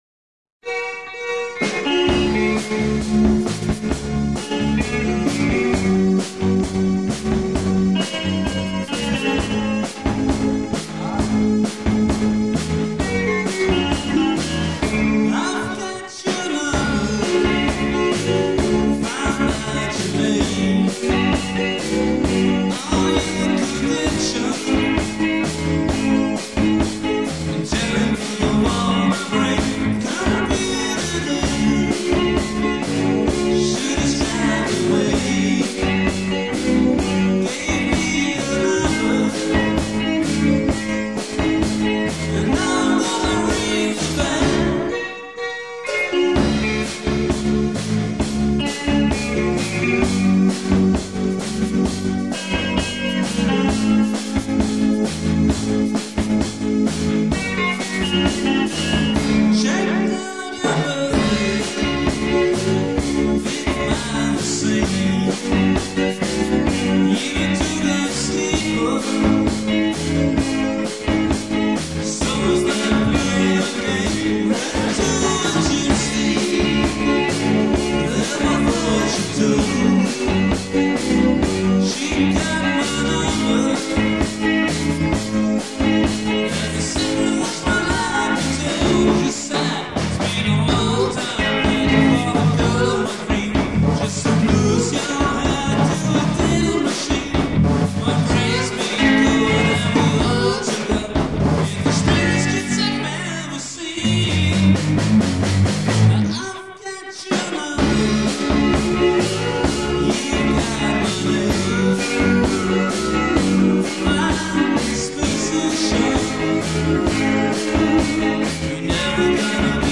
Livemitschnitt während eines Tanzabends in Wiesede/Ostfriesland.
Gitarre und Gesang
Keyboard und Gesang
Schlagzeug und Gesang
Bass und Gesang
Die Aufnahmen haben im Laufe der Jahrzehnte doch schon etwas gelitten.